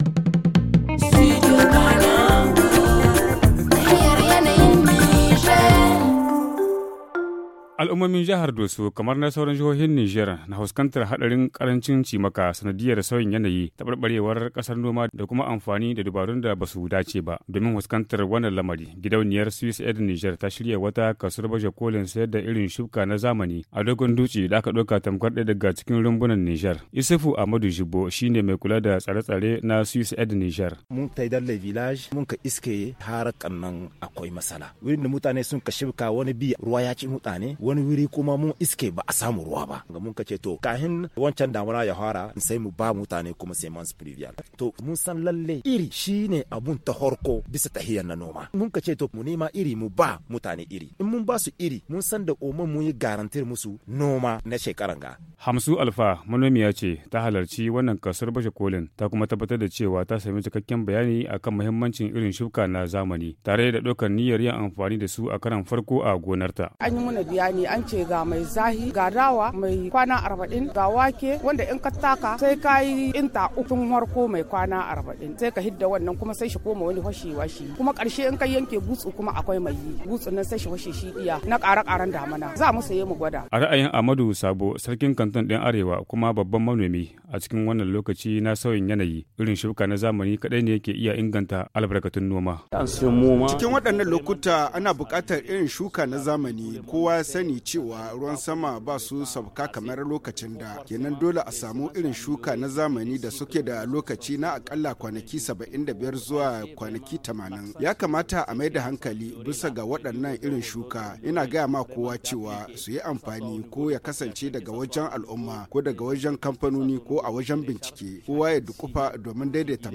Le magazine en haoussa